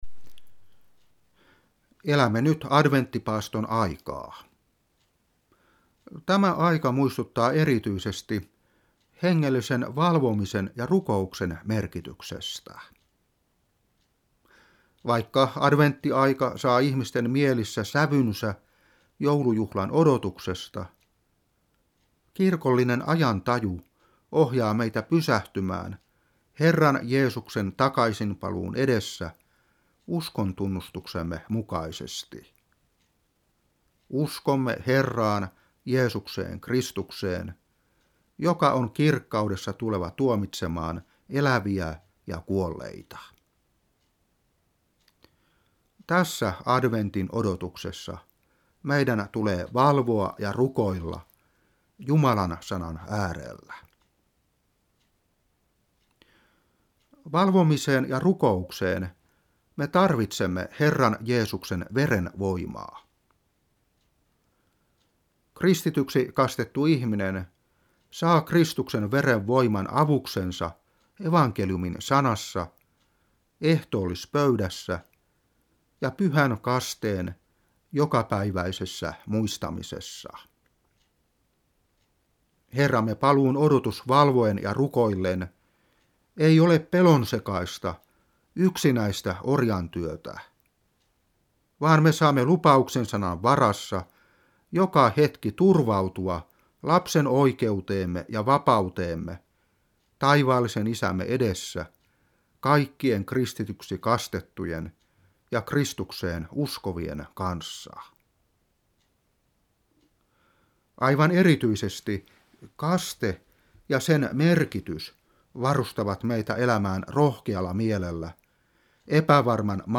Opetuspuhe 2020-11.